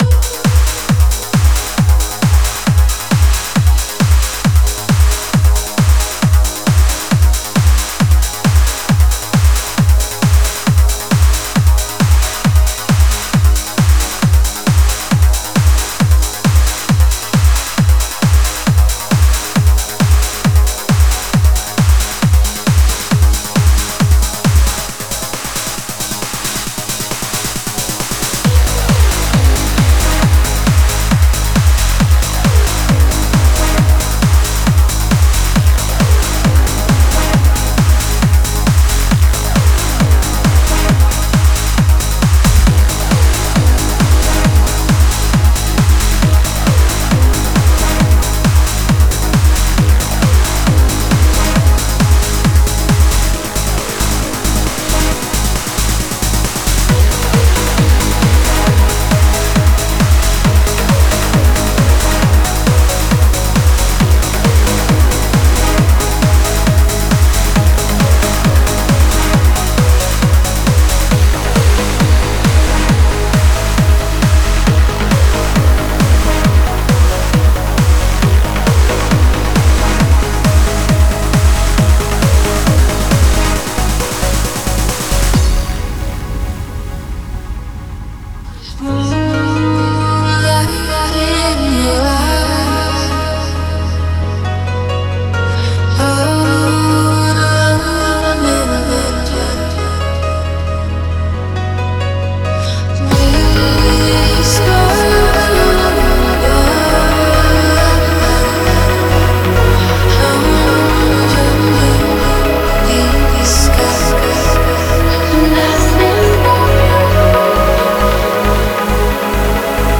Nothing But You [Vocal Trance
Вокал с Суно.